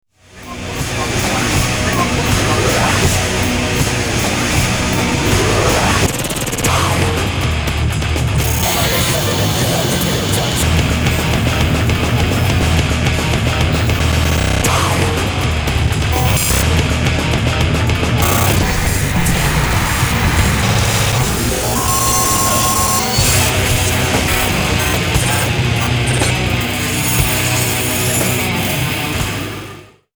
For noise sickos only!
3 song remix CD is pure digital regurgitation